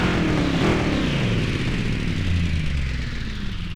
Index of /server/sound/vehicles/lwcars/buggy
slowdown_slow.wav